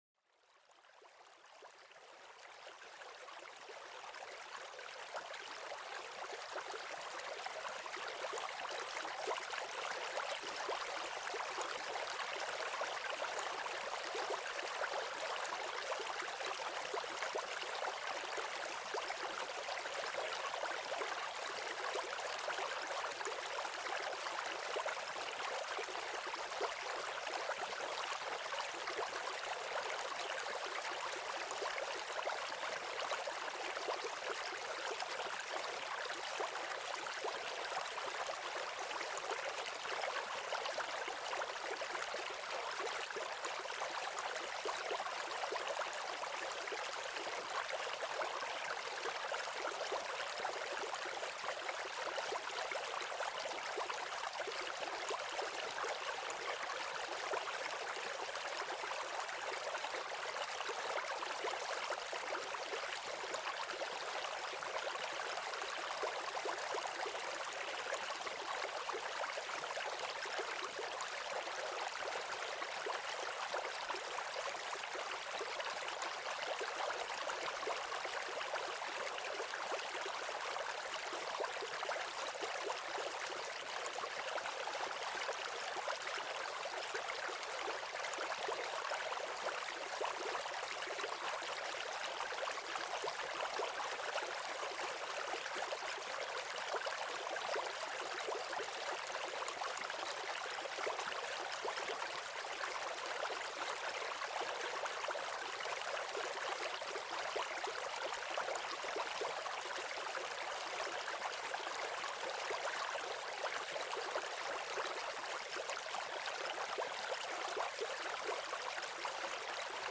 Harmonie Liquide: Plongez dans les bienfaits apaisants du murmure d'une rivière
Découvrez le son pur et cristallin de l'eau qui danse sur les pierres d'une rivière paisible.Laissez-vous transporter par cette symphonie naturelle qui apaise l'esprit et revitalise l'âme.Chaque goutte raconte une histoire de calme et de sérénité, idéale pour une pause détente ou une aide au sommeil.Ce podcast explore les sons apaisants de la nature, offrant aux auditeurs une échappatoire vers la tranquillité.Chaque épisode est une immersion sonore destinée à aider à la relaxation et au sommeil.